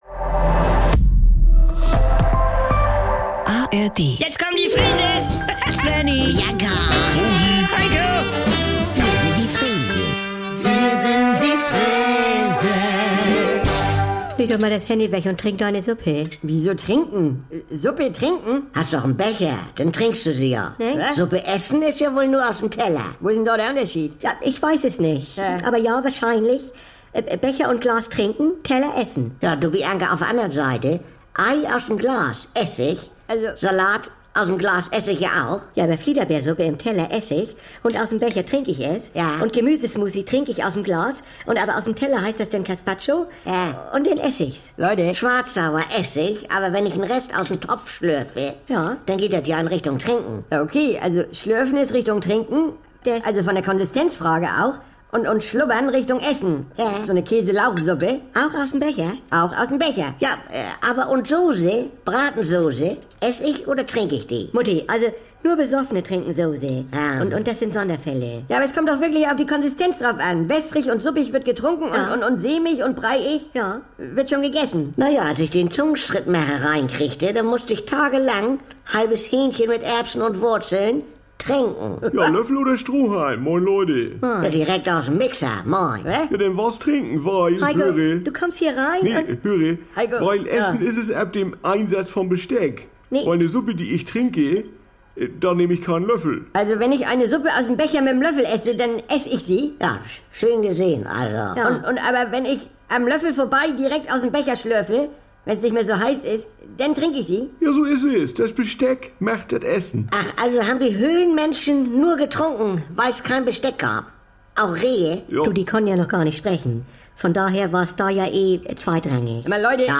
Saubere Komödien NDR 2 Komödie Unterhaltung NDR Freeses Comedy